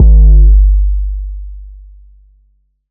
DDWV 808 4.wav